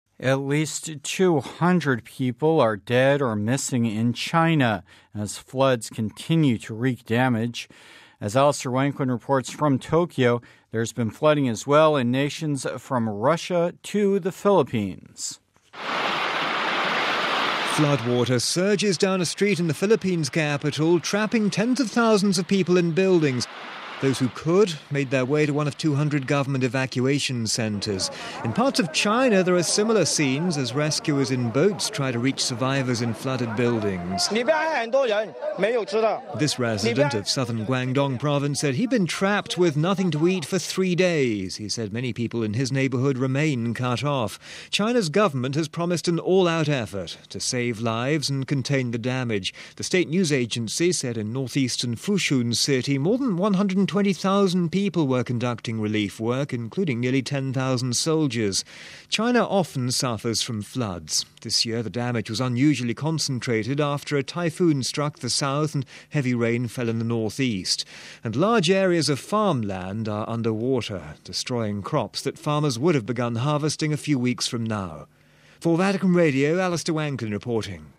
This resident of southern Guangdong province said he'd been trapped with nothing to eat for three days.